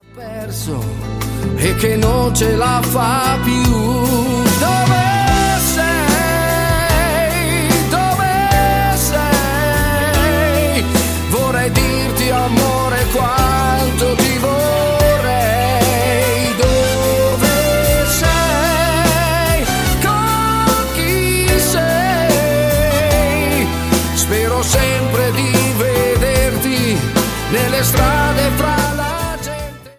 SLOW DUINE  (04,10)